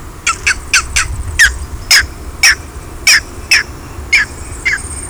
Green Heron
Butorides virescens
VOZ: Un sonoro "qui≤u", seguido por un rßpido cloqueo al ser molestada.